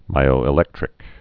(mīō-ĭ-lĕktrĭk)